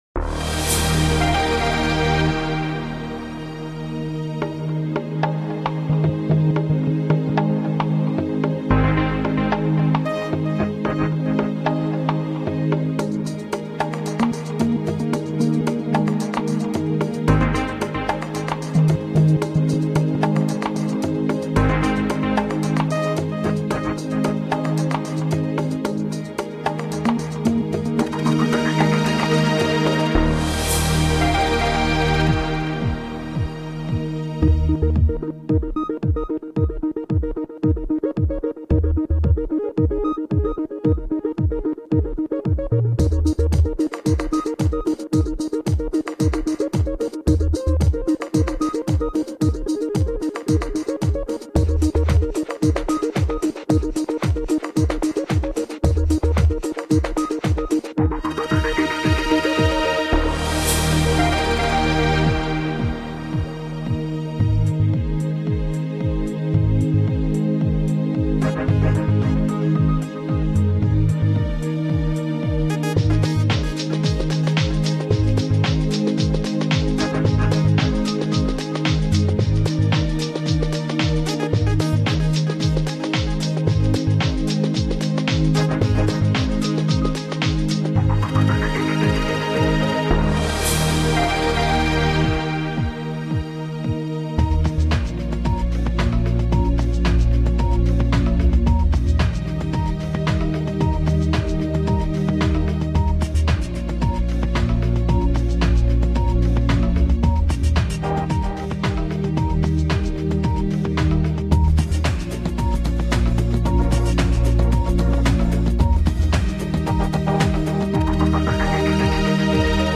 Ca donne une espèce de musique électro vive mais reposante.
Voilà une sorte de mix de l'album :